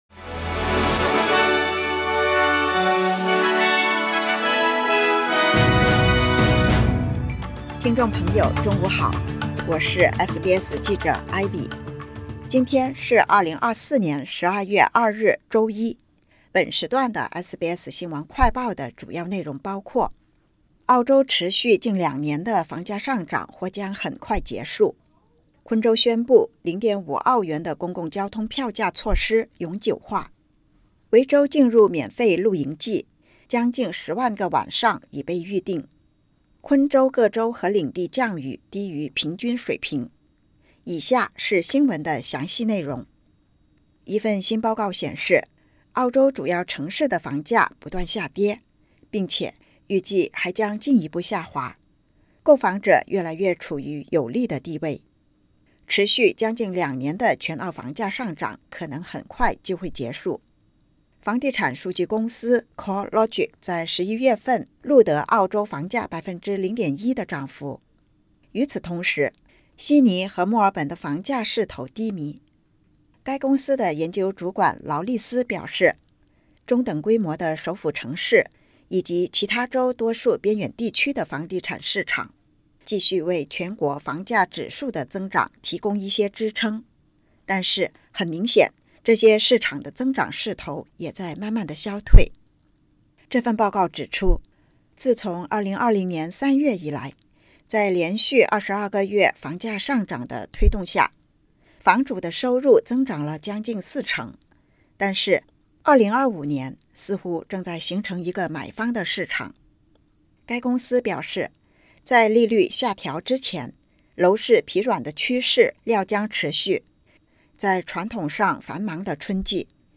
【SBS新闻快报】澳洲主要城市房价下跌 近两年增长趋势或终结